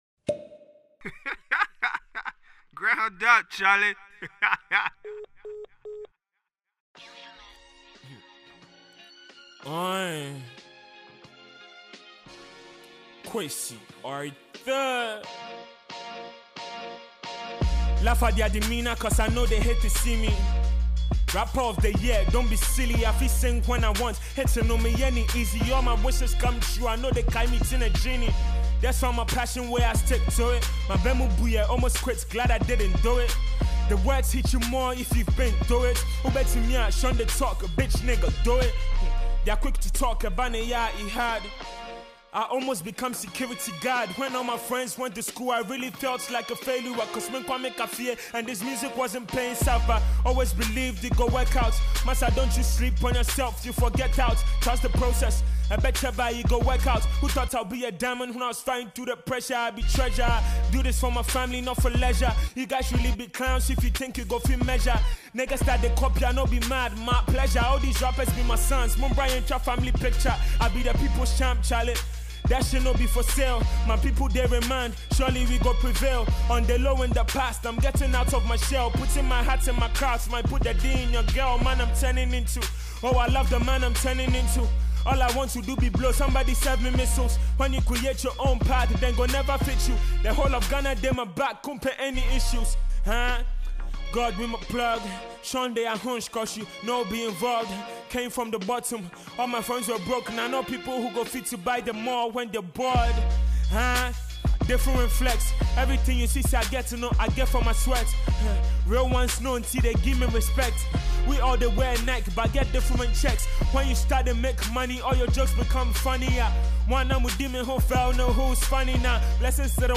freestyles